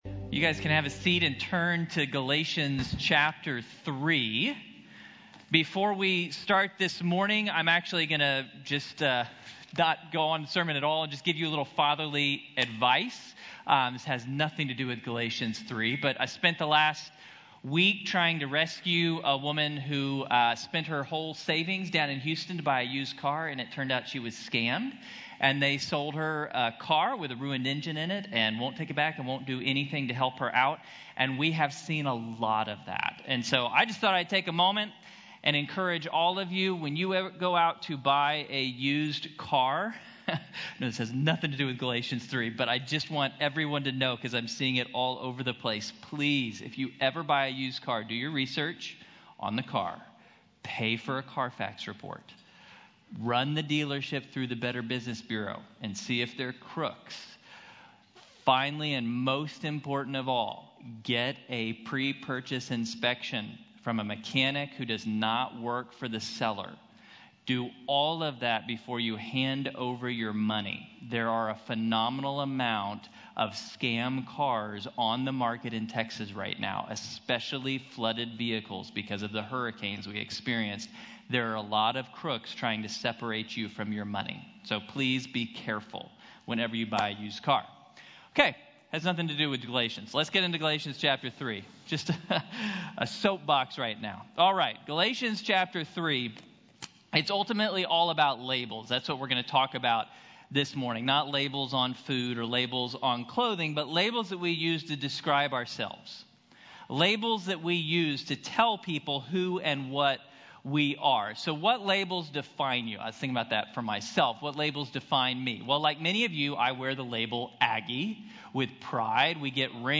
Sons and Heirs | Sermon | Grace Bible Church